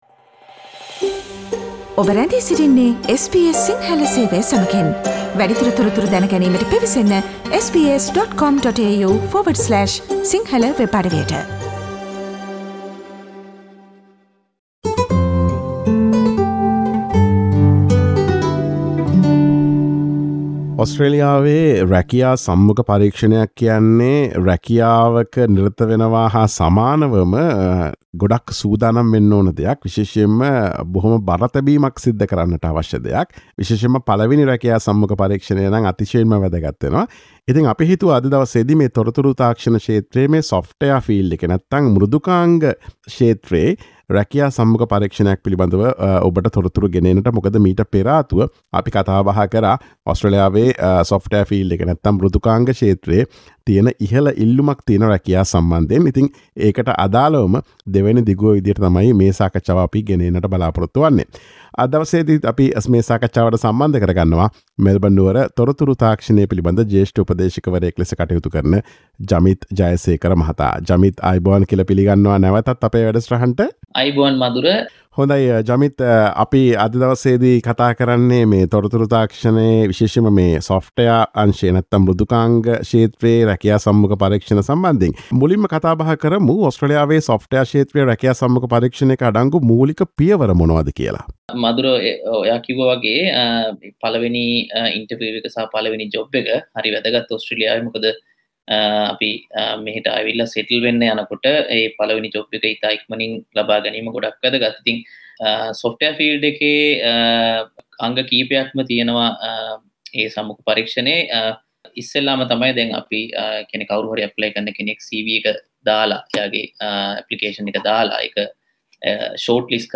ඔස්ට්‍රේලියාවේ මෘදුකාංග ක්ෂේත්‍රයේ රැකියා සම්මුඛ පරීක්ෂණයක අඩංගු ප්‍රධාන පියවර සහ අදාළ සමාගම් අයදුම්කරුවන්ගෙන් බලාපොරොත්තුවන දේ පිළිබඳ SBS සිංහල ගුවන් විදුලිය සිදුකළ සාකච්ඡාවට සවන් දෙන්න.